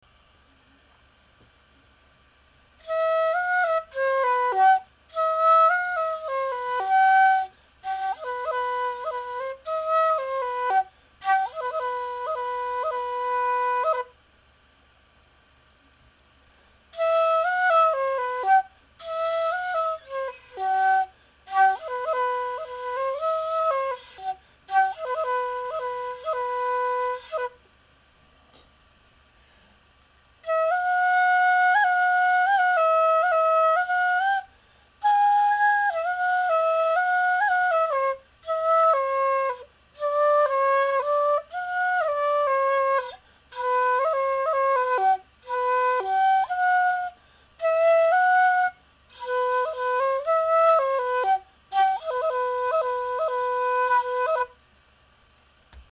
笛は未熟者で恐縮ですが、私の笛を録音させていただきました。
笛のメロディ